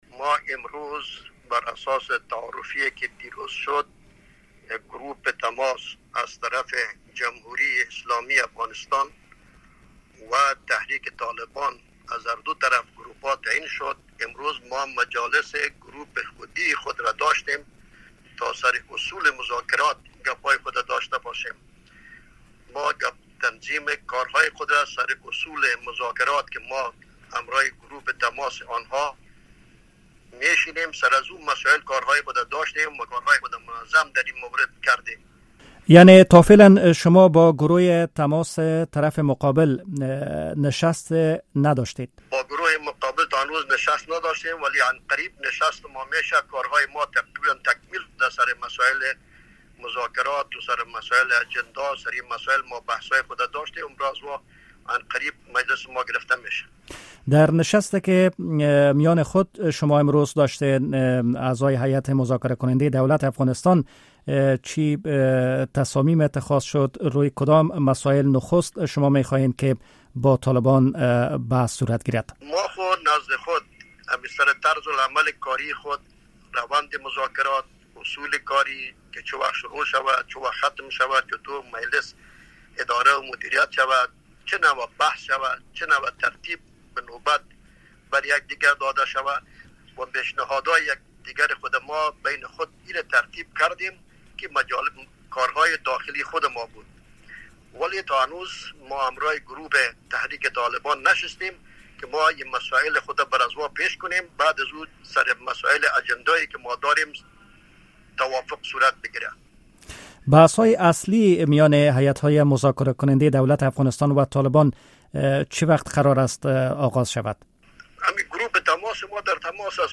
از طریق تلفون گفت‌وگو کرده و در آغاز از او پرسیده که آیا این نشست برگزار شد؟